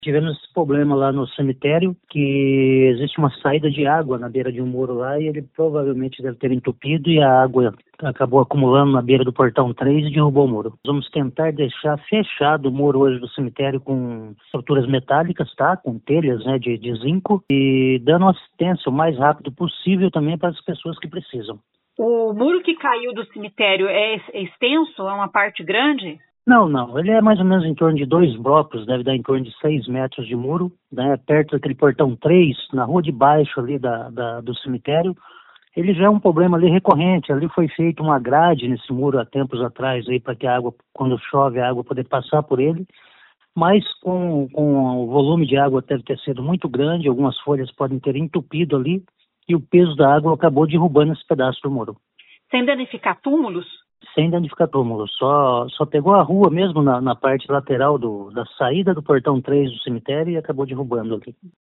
Ouça o que disse o secretário de Limpeza Urbana e coordenador da Defesa Civil, Vagner Mussio: